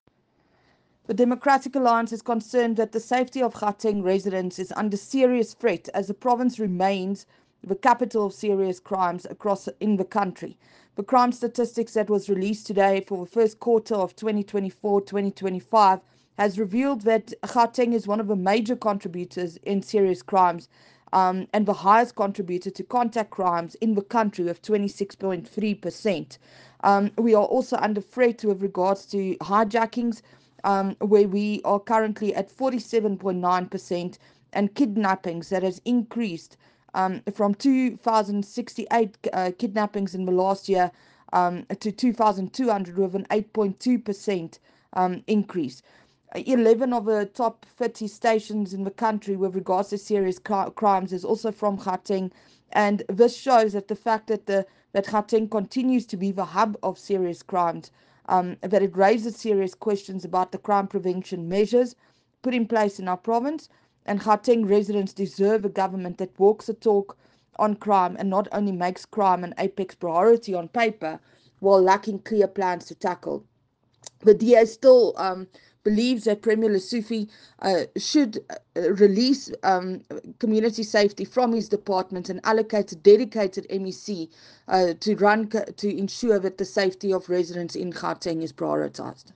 Note to Editors: Please find attached soundbites in
English and Afrikaans by Crezane Bosch MPL.
English-soundbite-Crezane-online-audio-converter.com_.mp3